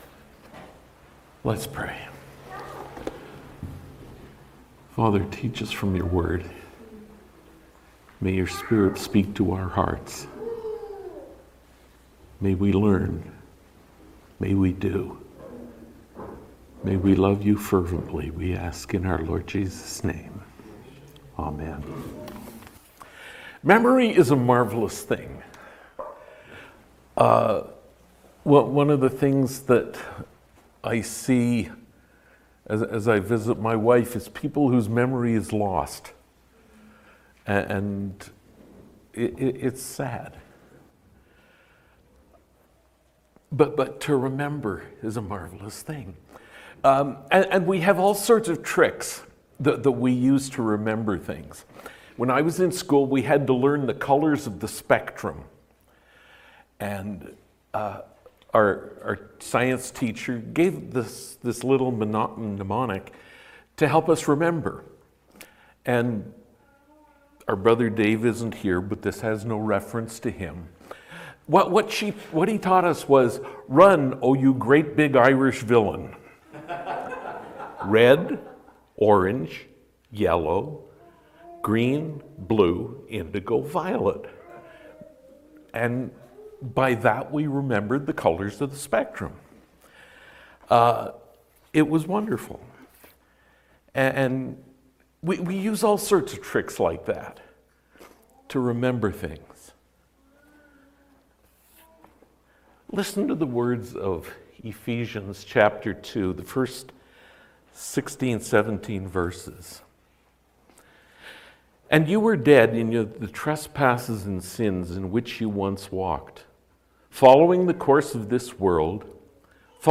Our weekly sermons are prepared and delivered by men in the assembly.
Sermons